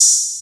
Open Hats
Digi OH.wav